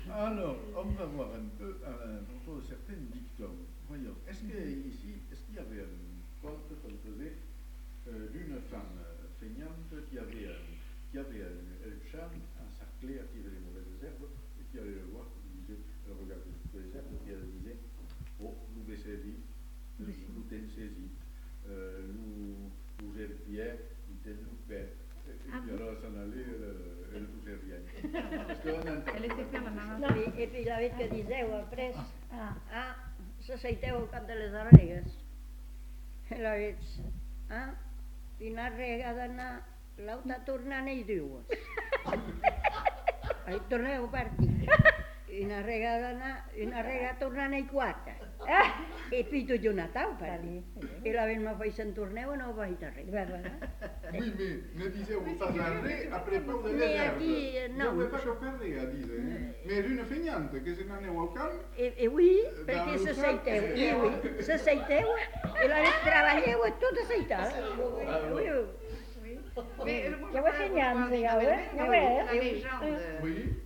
Lieu : Uzeste
Genre : conte-légende-récit
Effectif : 1
Type de voix : voix de femme
Production du son : parlé
Classification : récit anecdotique